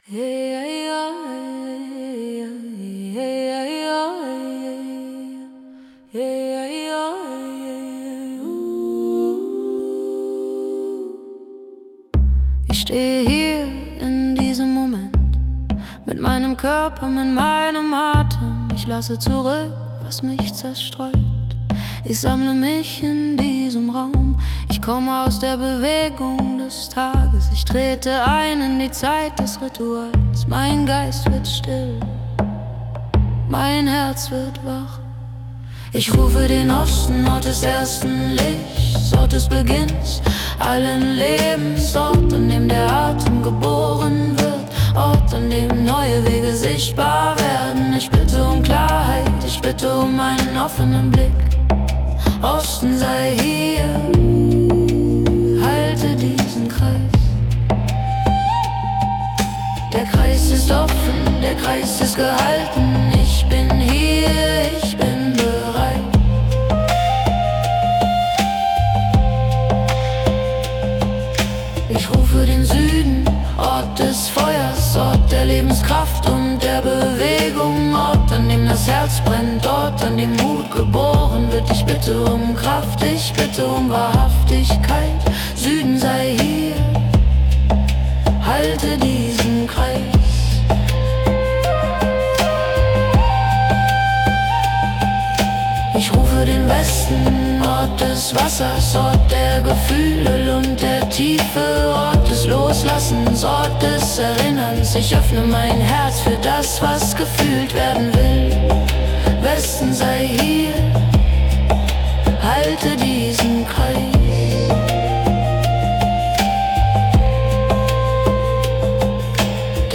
Die Lieder sind lang, ruhig, repetitiv und bewusst schlicht.
• klare Sprache, langsamer Puls